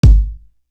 For The Record Kick.wav